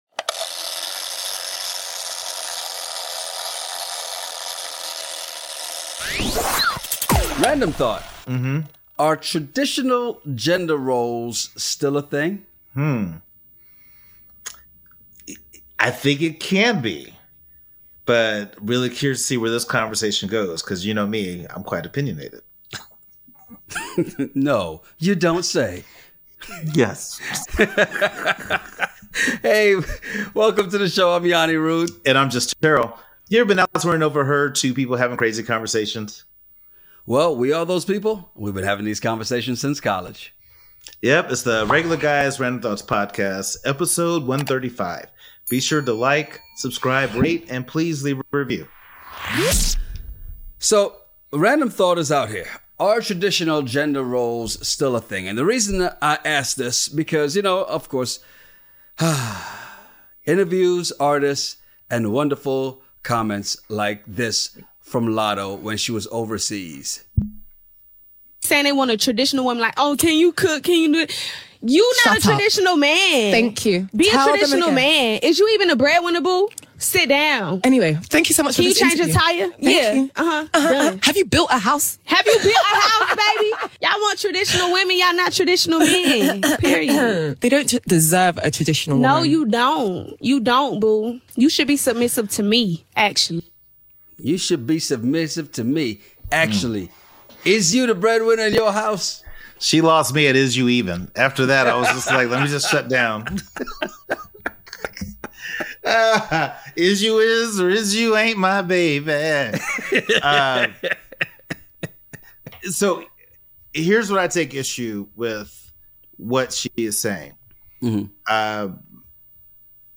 Why are so many people mad at Barbie? 00:00 Intro 00:50 Traditional Gender Roles 20:40 Mad At Barbie Ever been somewhere and overheard two guys having a crazy conversation over random topics?